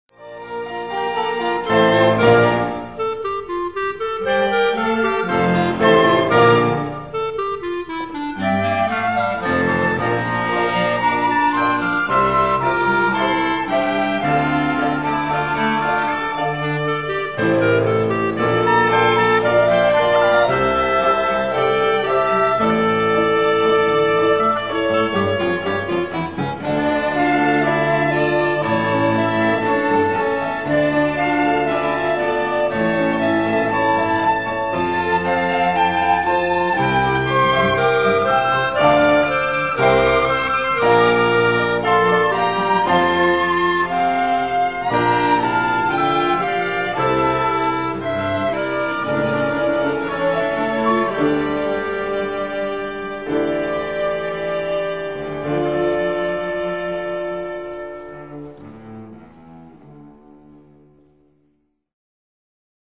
音楽ファイルは WMA 32 Kbps モノラルです。
Flute、Oboe、Clarinet、Violin、Cello、Piano
（259,638 bytes） 妖しくおどろおどろしくも、神秘的で荘厳な迷宮へと誘う。